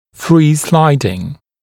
[friː-‘slaɪdɪŋ][фри:-‘слайдин]свободно скользящий